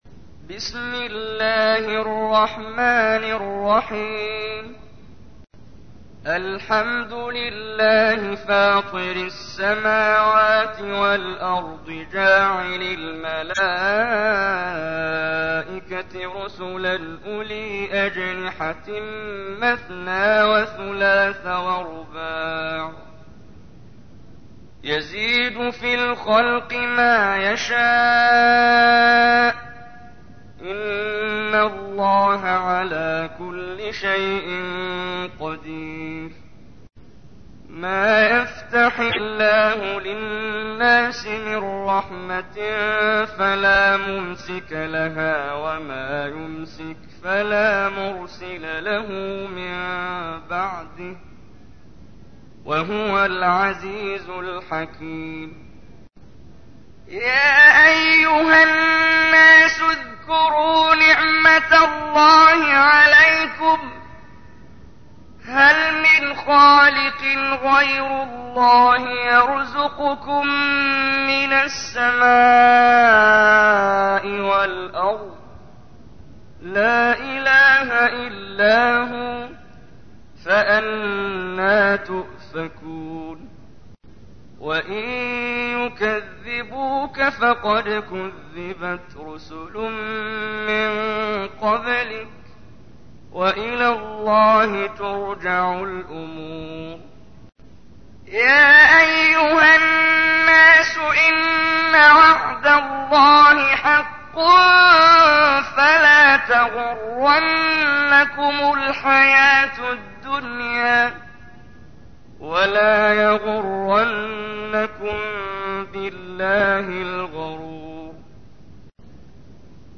تحميل : 35. سورة فاطر / القارئ محمد جبريل / القرآن الكريم / موقع يا حسين